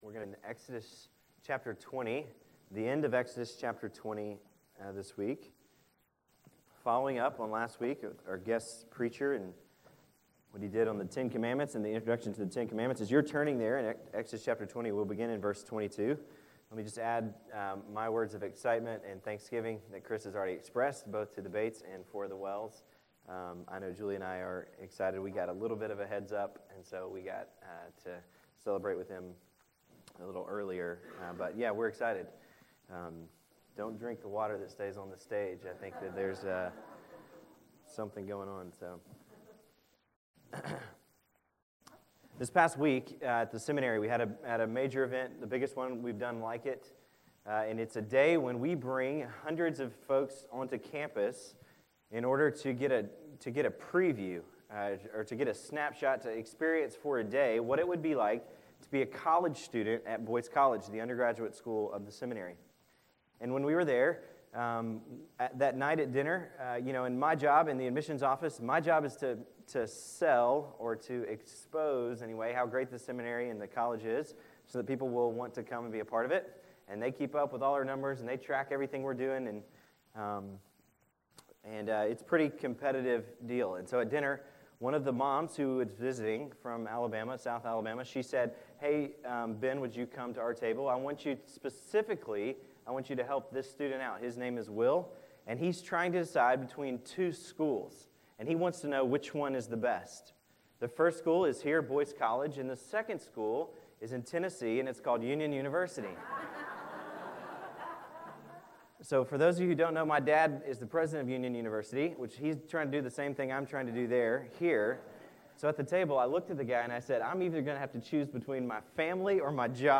October 27, 2013 AM Worship | Vine Street Baptist Church